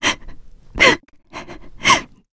Valance-Vox_Sad_kr.wav